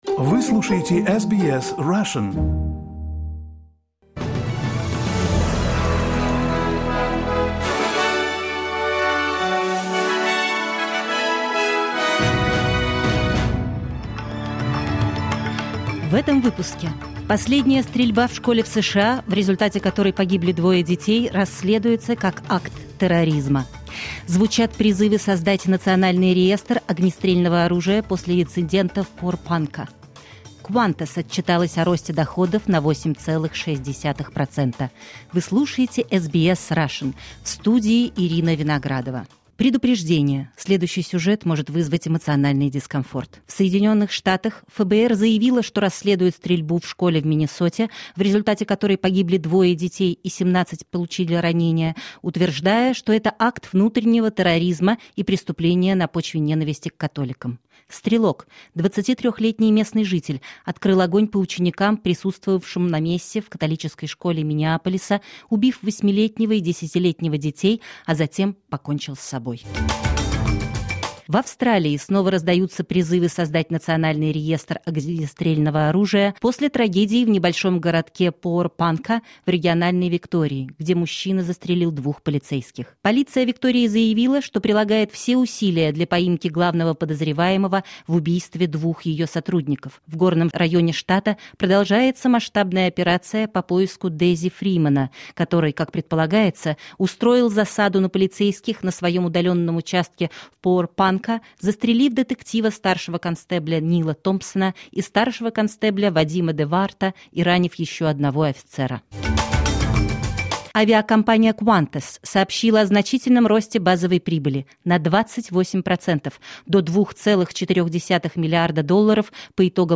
Новости SBS на русском языке — 28.08.2025